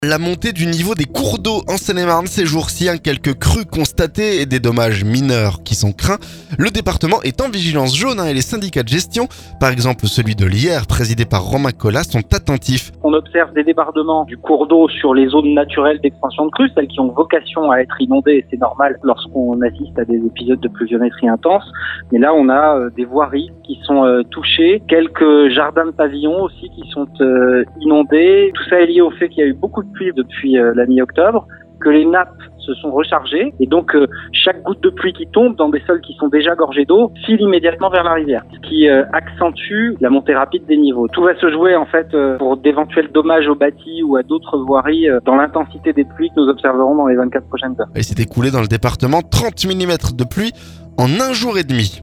CRUES - "On a des voiries et quelques jardins inondés" témoigne le président du SYAGE